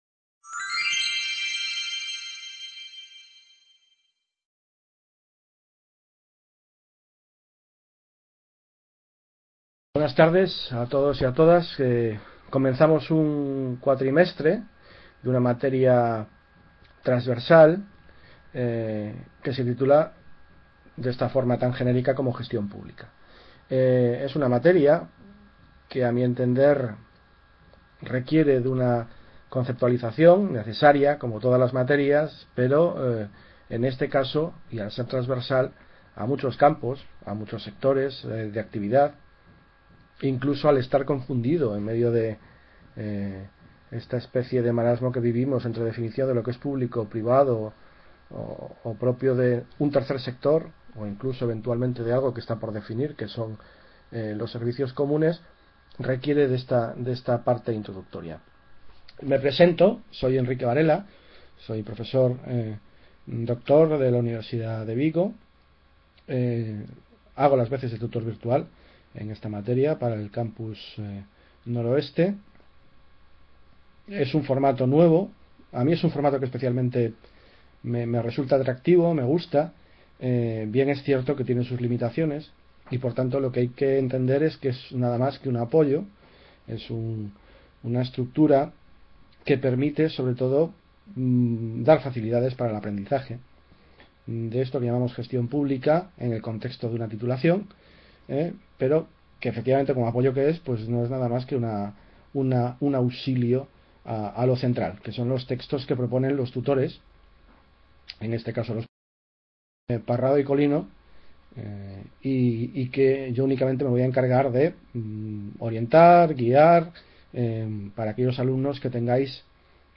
Webconferencia Gestión Pública (1-III-2013). Tema 1 …